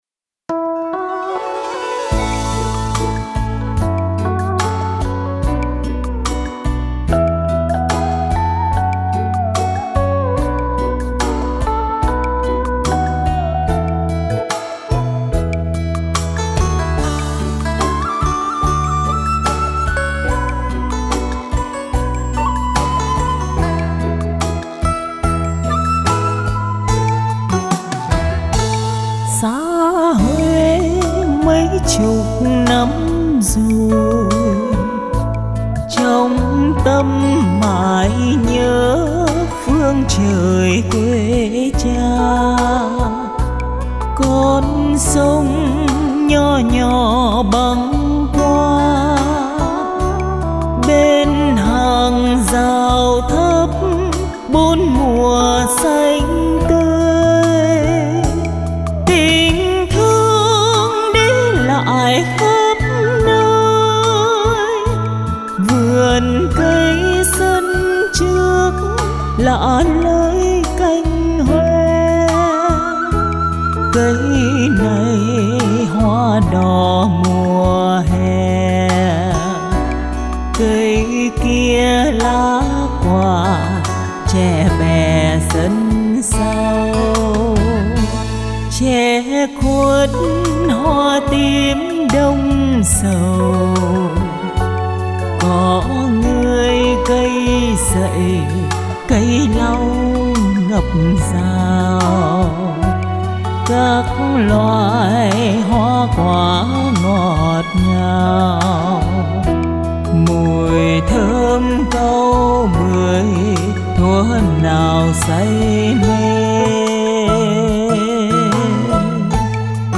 Tiếng h�t